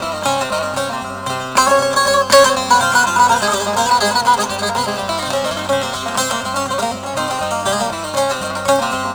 SAZ 05.AIF.wav